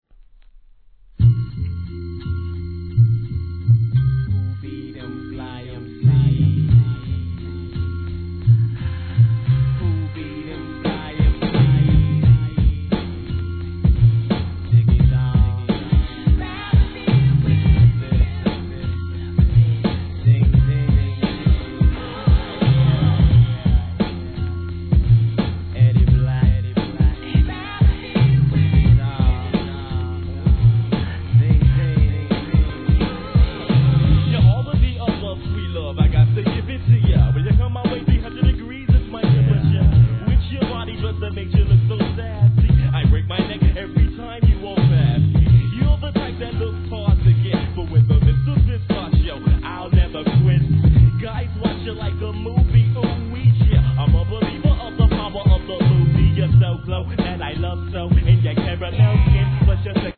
HIP HOP/R&B
コーラスフックで聴かせる1995年メロ〜HIP HOP!